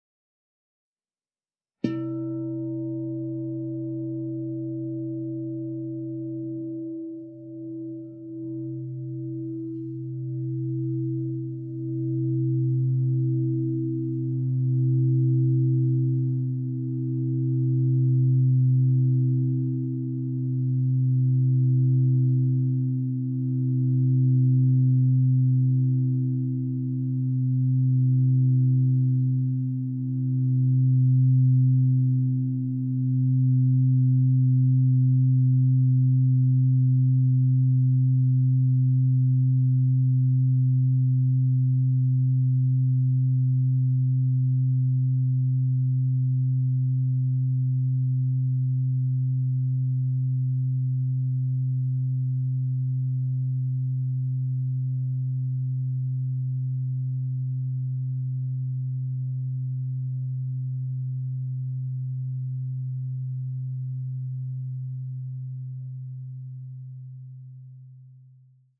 Meinl Sonic Energy 16" white-frosted Crystal Singing Bowl C3, 432 Hz, Wurzelchakra (CSB16C3)
Produktinformationen "Meinl Sonic Energy 16" white-frosted Crystal Singing Bowl C3, 432 Hz, Wurzelchakra (CSB16C3)" Die weiß-matten Meinl Sonic Energy Crystal Singing Bowls aus hochreinem Quarz schaffen durch ihren Klang und ihr Design eine sehr angenehme Atmosphäre.